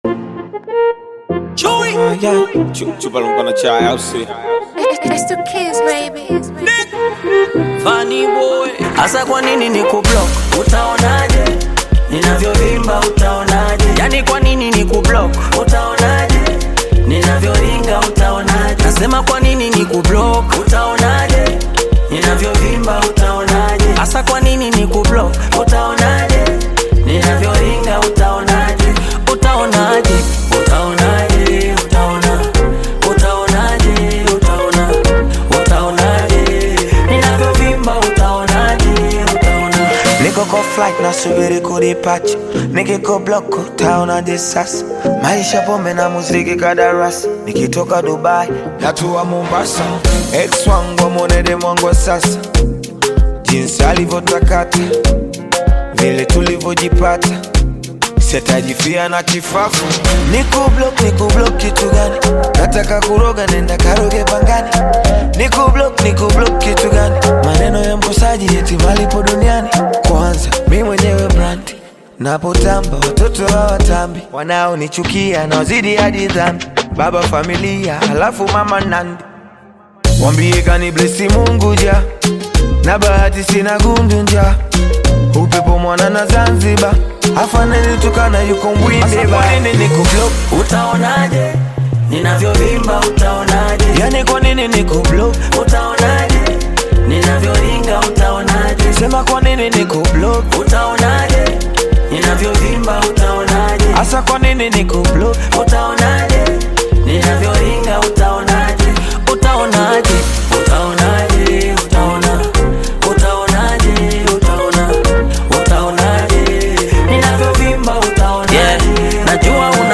Tanzanian bongo flava artist